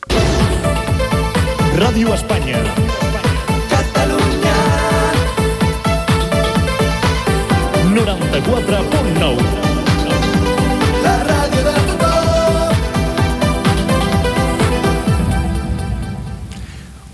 Indicatiu de l'emissora
Banda FM